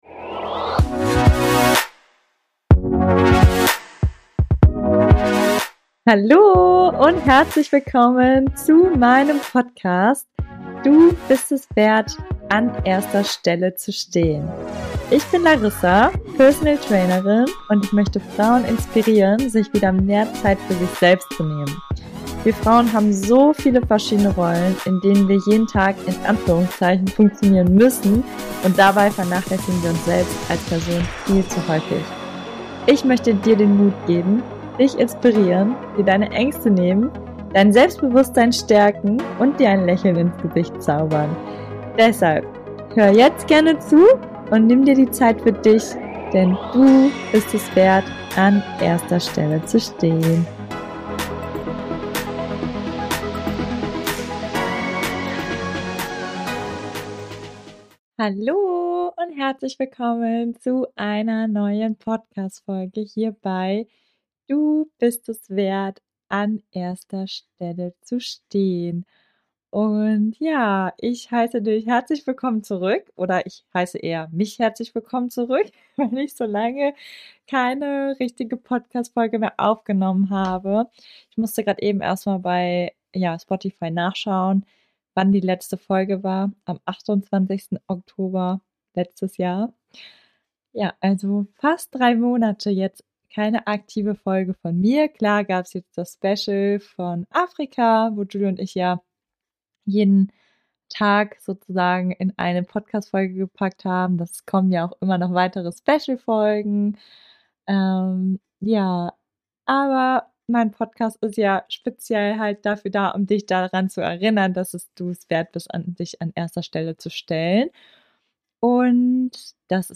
Heute erzähle ich dir von dem, was ich wahrnehmen durfte und ich möchte dir nahelegen, dich auch selbst noch mehr mit dir zu beschäftigen. Damit du in deine volle Energie kommst und gesund bleibst oder auch Krankheiten schneller heilen kannst. Es ist eine etwas emotionalere Folge und auch etwas unstrukturiert, aber ich spreche einfach aus meinem Herzen und hoffe, dass ich dich damit inspirieren kann.